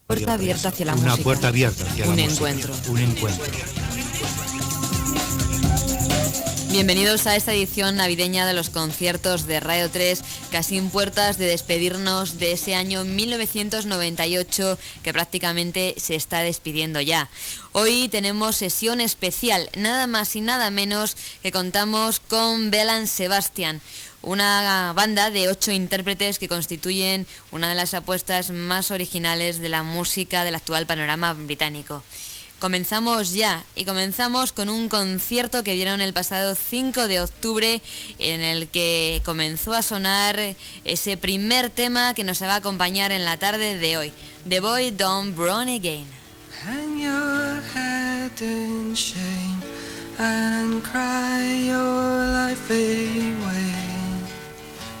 Careta del programa, presentació de l'espai dedicat al concert de Belle and Sebastian fet a França el 10 de maig de 1998